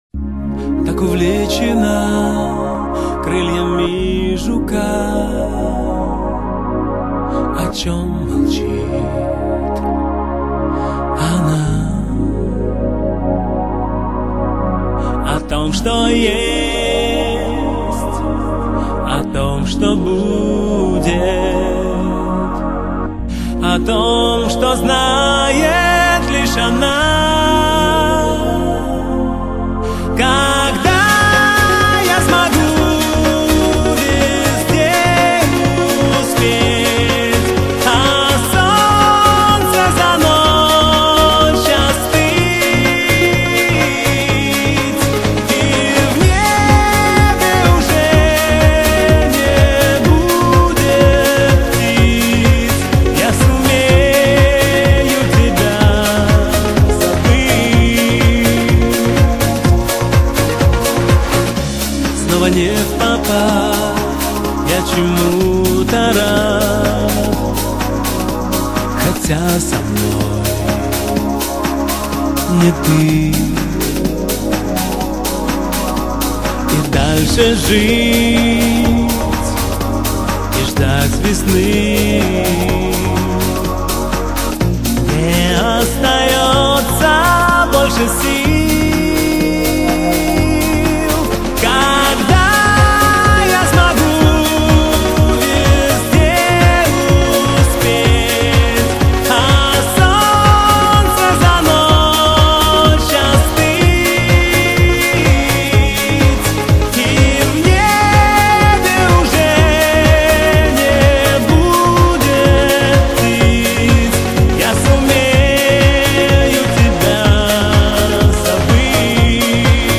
это эмоциональная композиция в жанре поп с элементами фолка.